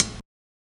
Closed Hats
HIHAT GRITTY II.wav